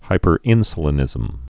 (hīpər-ĭnsə-lə-nĭzəm)